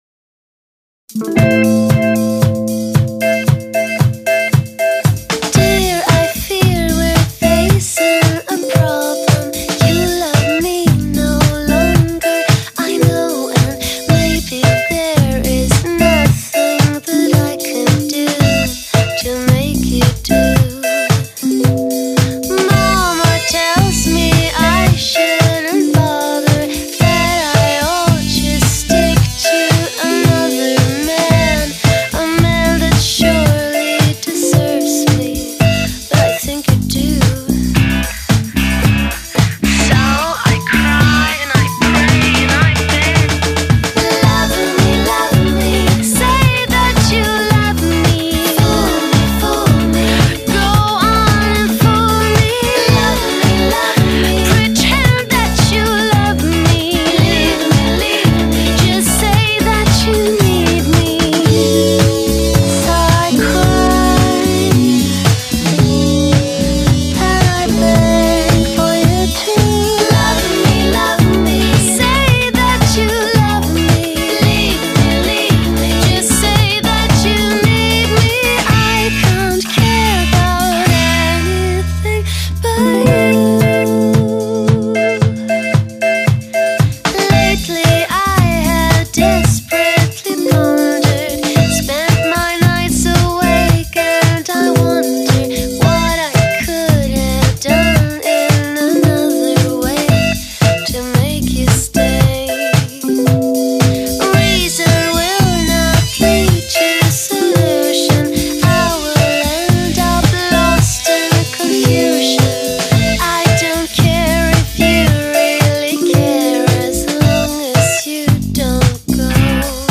这是一张欧美流行音乐的合辑，这个专辑年初时抓的碟，现在不知把专辑放到哪里去了，所以，就不上传专辑封面了。